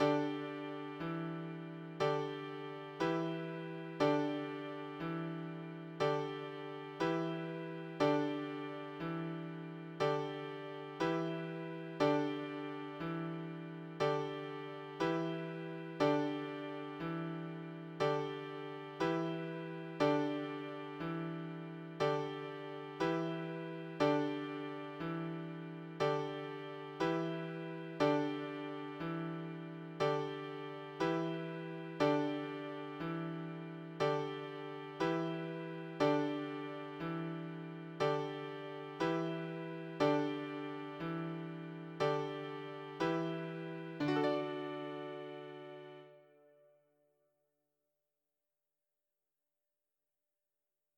1.5 (6) - Accompaniment mp3 - Summer's Coming.mp3